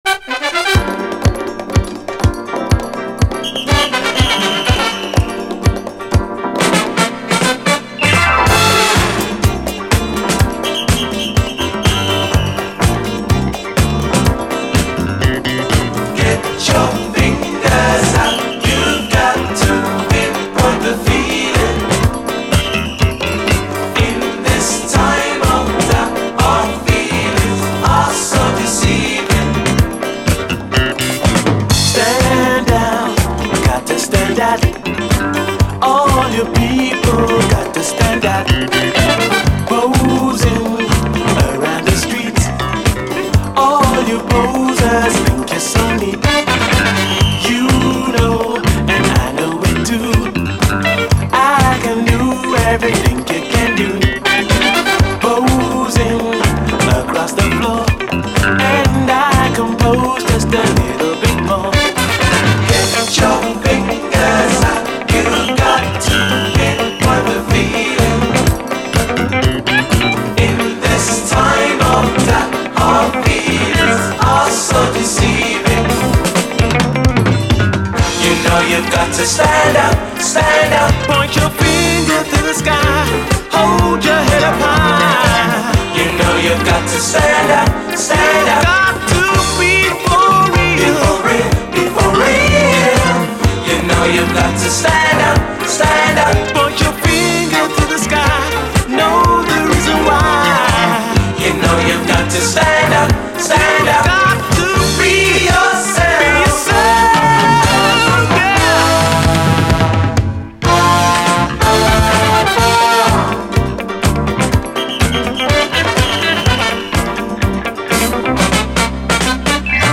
SOUL, 70's～ SOUL, DISCO
両面アルバム未収録のグレイト・ブリット・ファンク〜爽快UKモダン・ブギー！